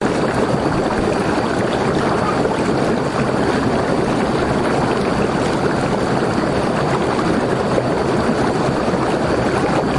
Summers evening with bubbling spa water in the background
标签： Spa babbling splash running water bath onsen
声道立体声